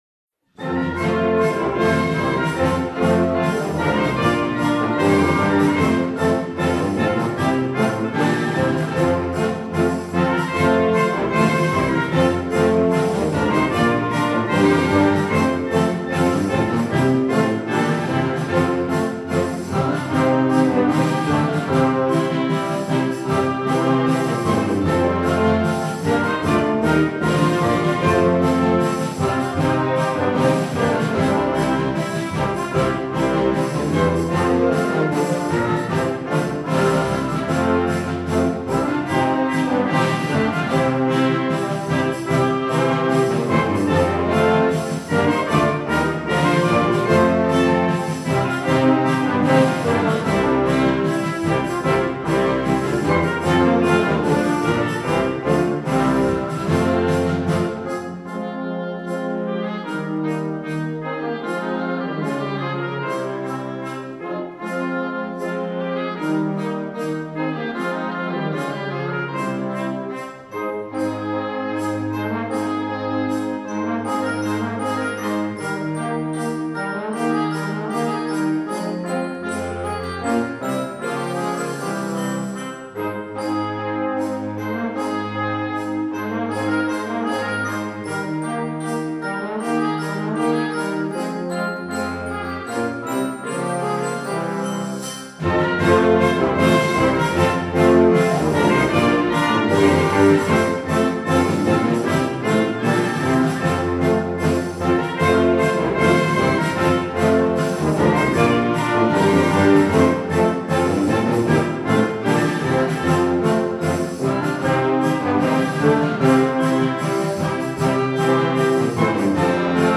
Instrumentation: concert band
classical, children